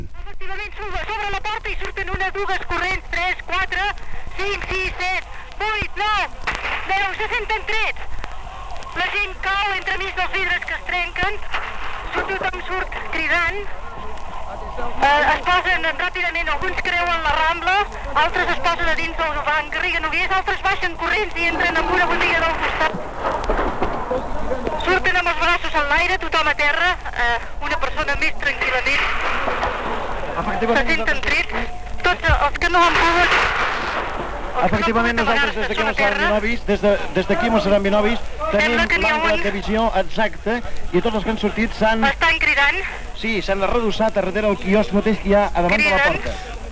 Narració del moment de la sortida dels ostatges retinguts, des del dia abans, al Banco Central de Plaça de Catalunya amb Rambles, a Barcelona
Informatiu